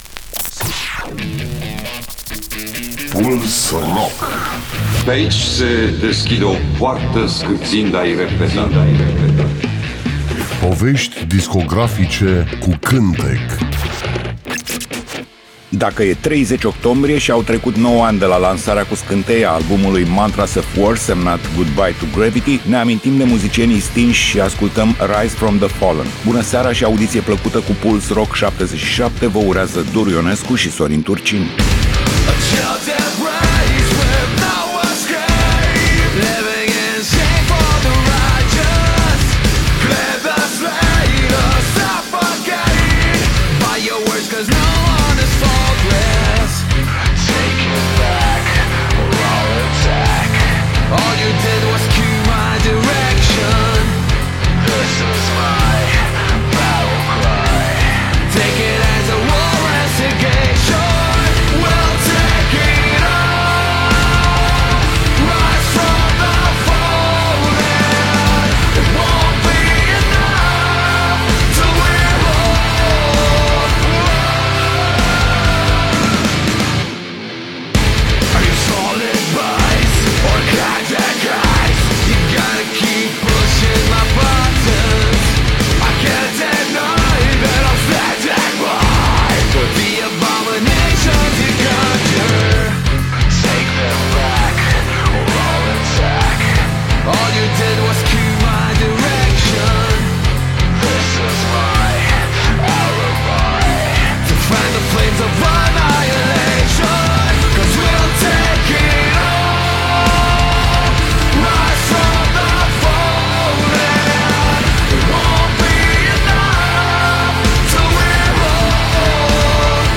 Emisiunea se numește Puls Rock și jonglează cu artiștii noștri rock, folk, uneori chiar jazz. Voi căuta lucruri interesante, valoroase, care au scăpat atenției publicului. Fiecare melodie pe care o voi prezenta are o poveste.